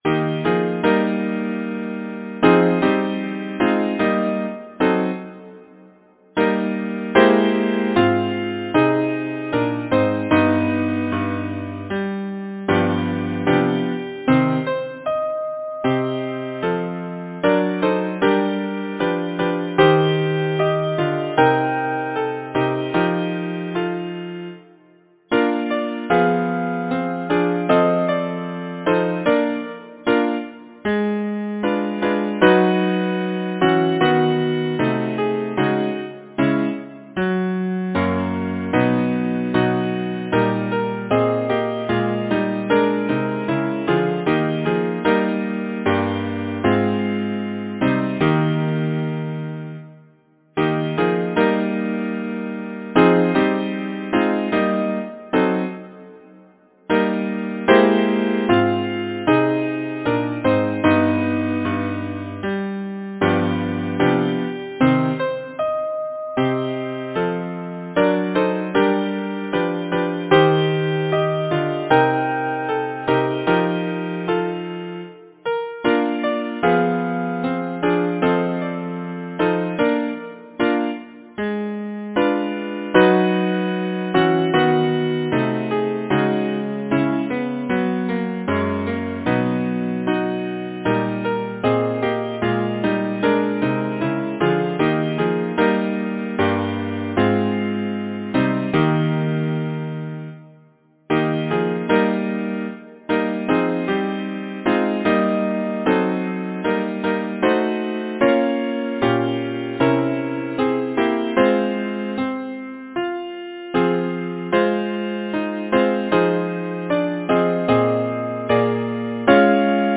Title: In a drear-nighted December Composer: John Pointer Lyricist: John Keats Number of voices: 4vv Voicing: SATB Genre: Secular, Partsong
Language: English Instruments: A cappella